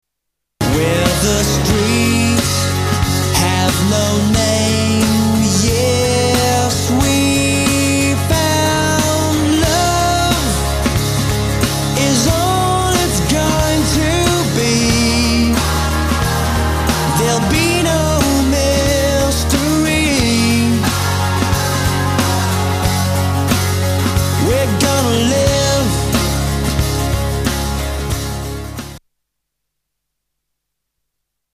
STYLE: Pop
they make jangly, ethereal yet anthemic pop